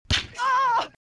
Smack